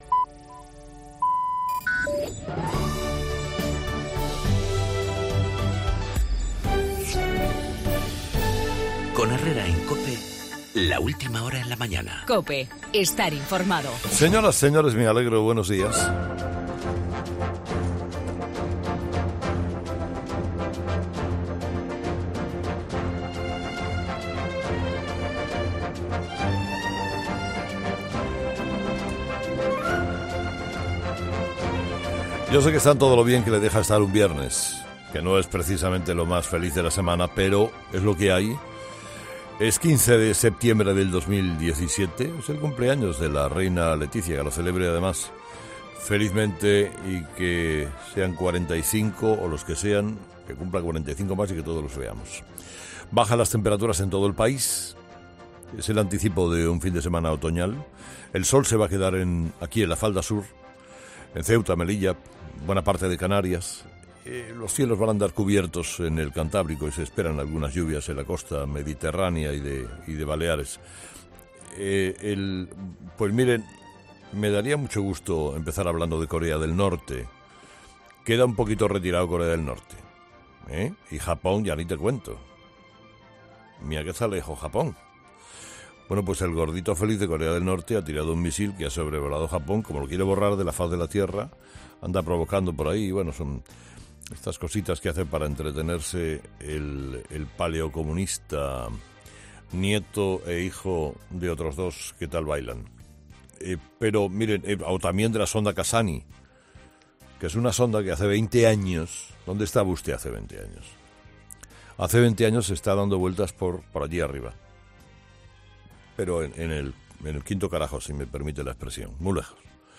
La alcaldesa y Puigdemont acuerdan que se pueda votar en Barcelona pero la edil no asume ninguna responsabilidad, en el editorial de Carlos Herrera